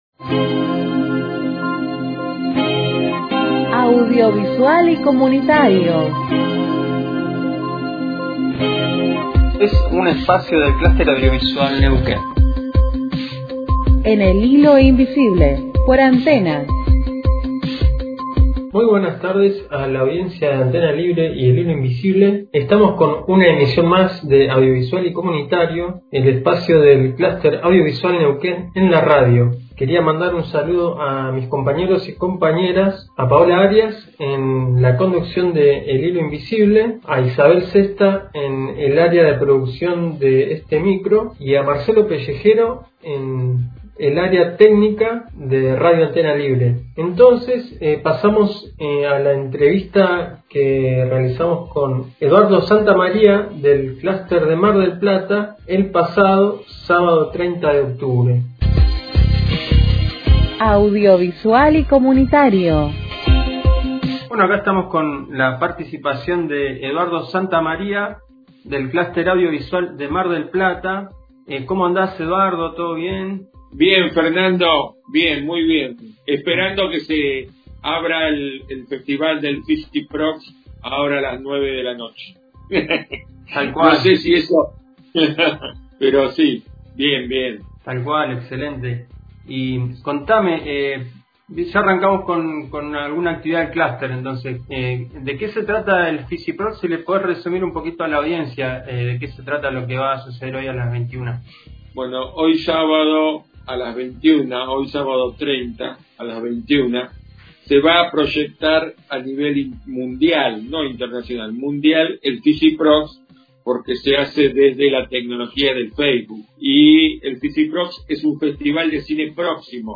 Micro espacio de radiodifusión de las actividades del Cluster Audiovisual Neuquén, todos los miércoles en El Hilo Invisible.